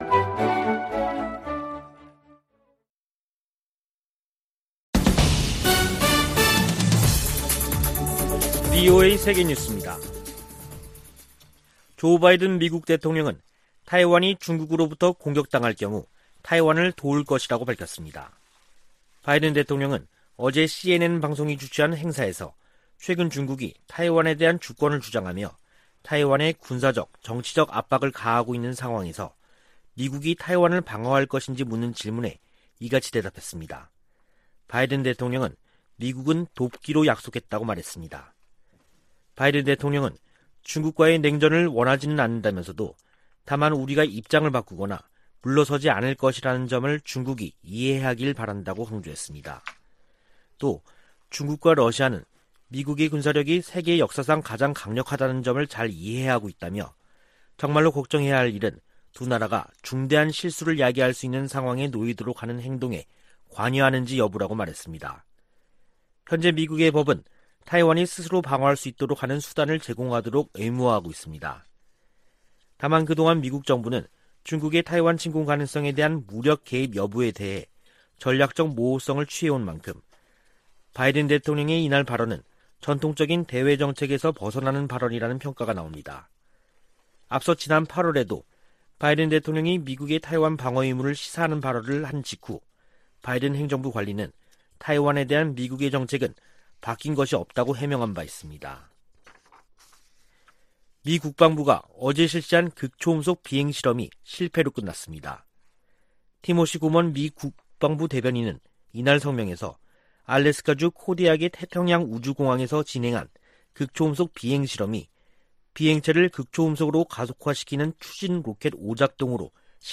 VOA 한국어 간판 뉴스 프로그램 '뉴스 투데이', 2021년 10월 22일 3부 방송입니다. 북한이 가능한 모든 영역에서 핵 개발에 전력을 다하고 있다고 국제원자력기구(IAEA) 사무총장이 지적했습니다. 미국은 제재 사용에 관해 동맹· 파트너 국가들과의 협의와 협력을 중시할 것이라고 미 재무부 부장관이 밝혔습니다. 북한이 여전히 대량살상무기 기술 이전 역할을 하고 있다고 낸시 펠로시 미 하원의장이 지적했습니다.